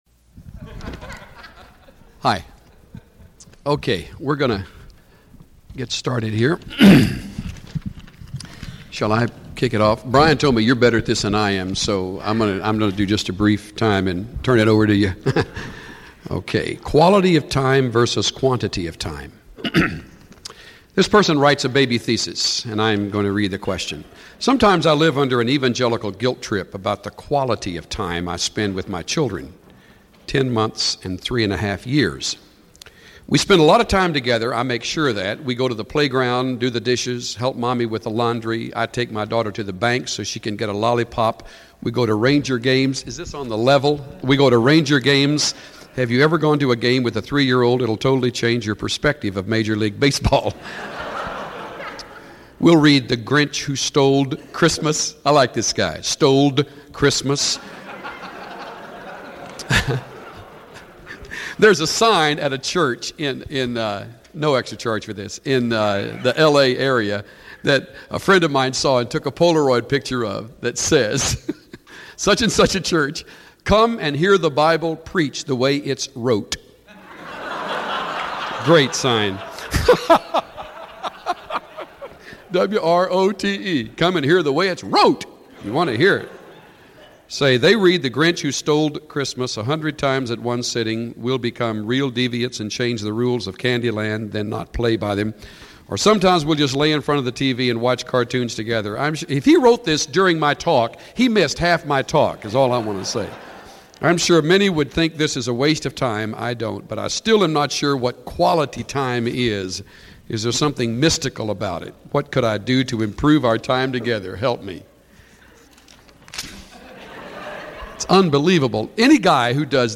Chuck Swindoll teaches on parenting and how to make time for communication with one's children.